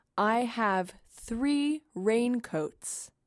标签： 听觉 女性 穿 雨衣 培训
声道立体声